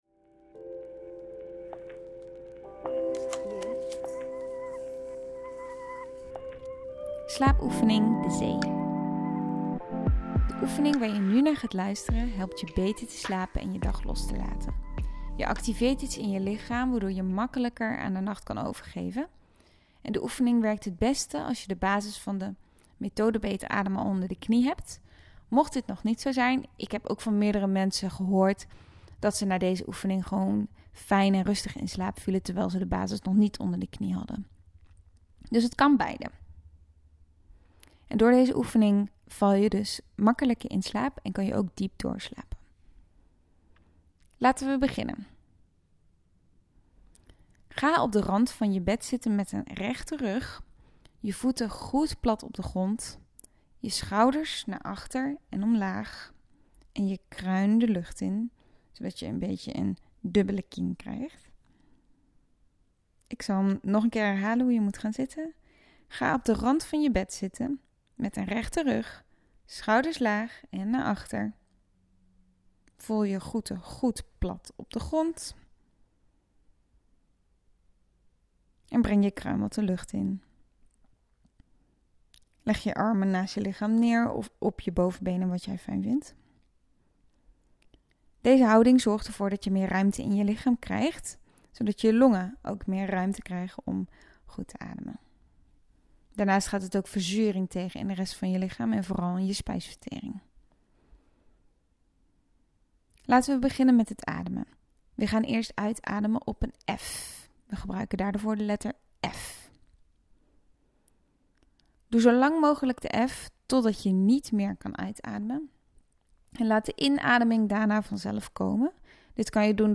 De zee oefening helpt je om het ritme te komen eb en vloed. Deze oefening heeft een ontspannende werking. Wanneer de ademt volgt klinkt het ook daadwerkelijk als de zee.
Slaap-oefening-De-Zee-.mp3